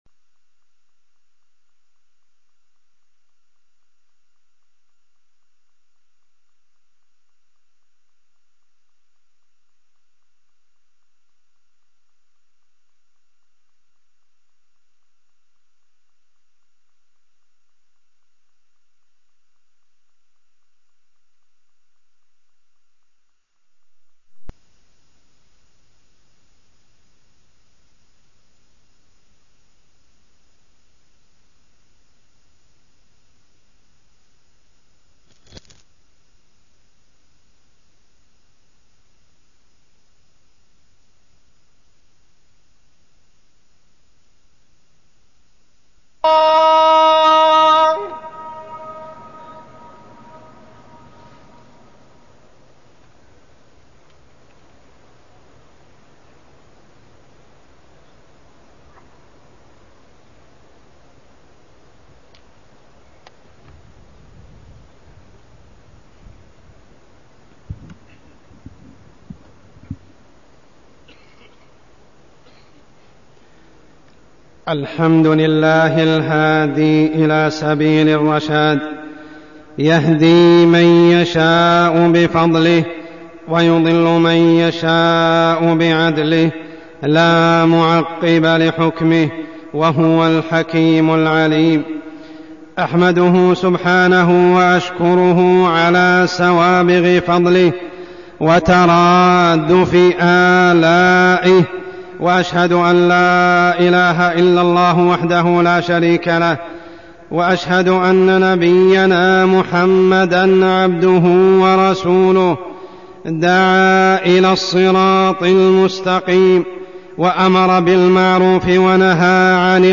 تاريخ النشر ١٥ ربيع الثاني ١٤١٩ هـ المكان: المسجد الحرام الشيخ: عمر السبيل عمر السبيل الأمر بالمعروف والنهي عن المنكر